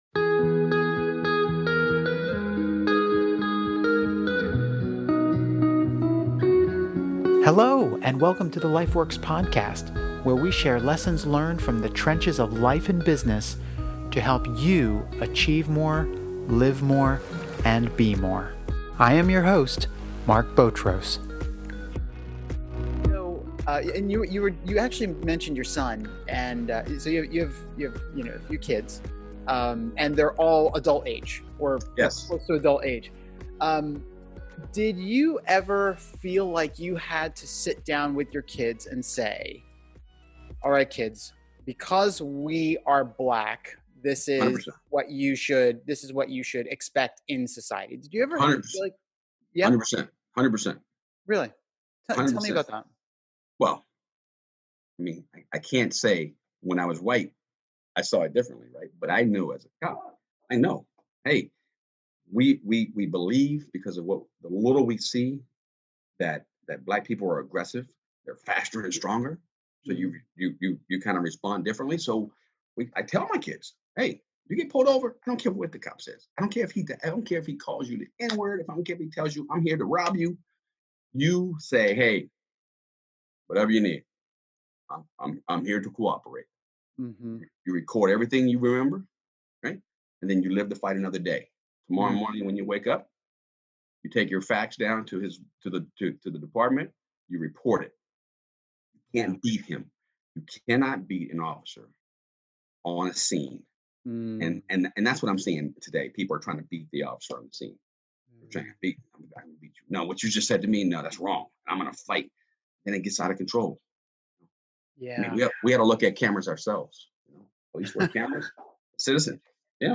Music Credit